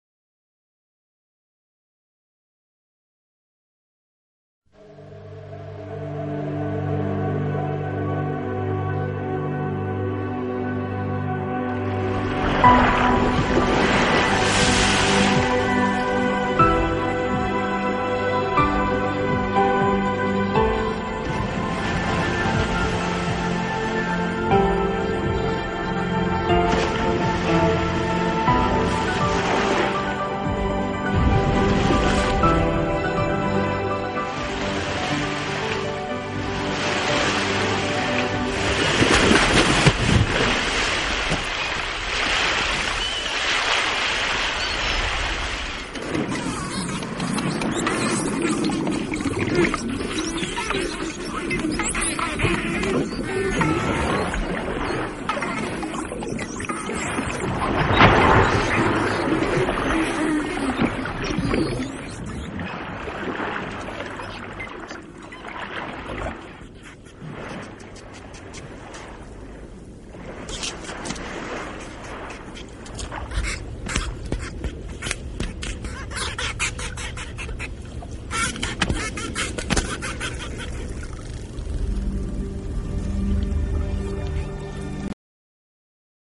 Comunicación
Los delfines emiten de forma continua chasquidos y silbidos.